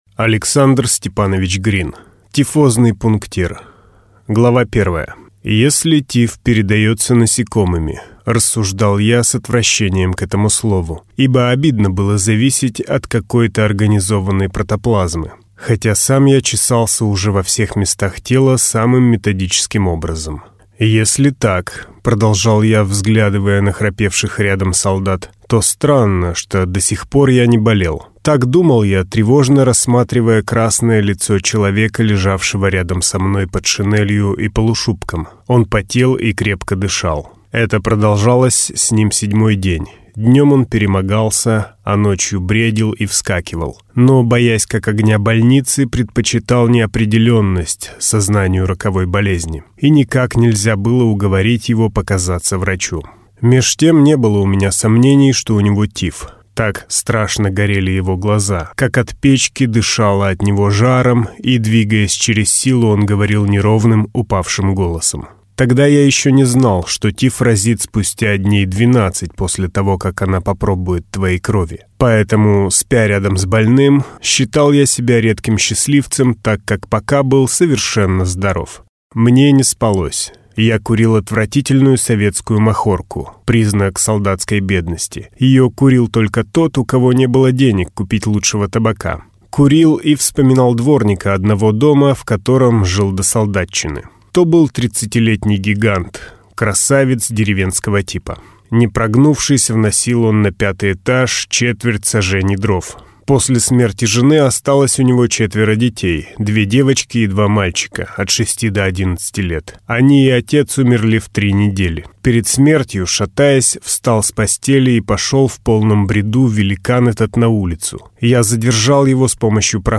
Аудиокнига Тифозный пунктир | Библиотека аудиокниг
Прослушать и бесплатно скачать фрагмент аудиокниги